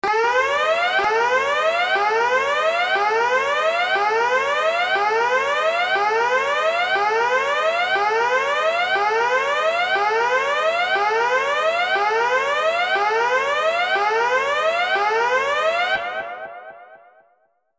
دانلود آهنگ هشدار 18 از افکت صوتی اشیاء
جلوه های صوتی
دانلود صدای هشدار 18 از ساعد نیوز با لینک مستقیم و کیفیت بالا